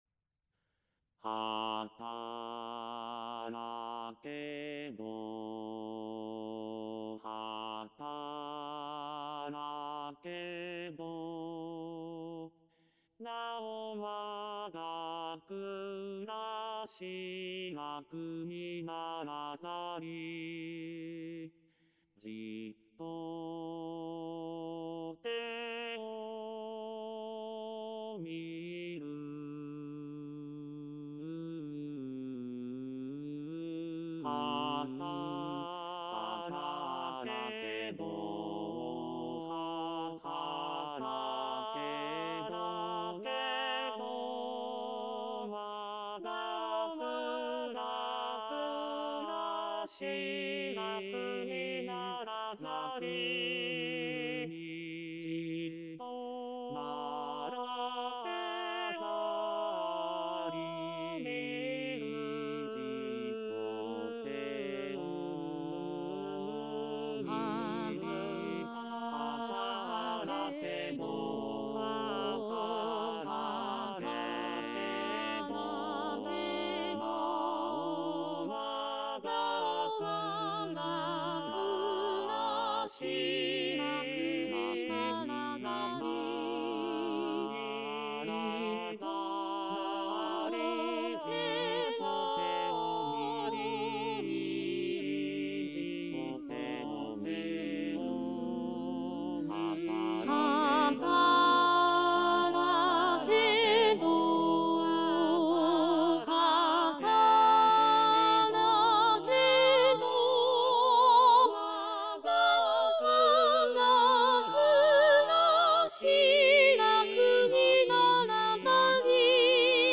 ●パート単独音源　　　■Rchソプラノ、Lch、アルト、テノール、バス
hatarakedo_bassueall.mp3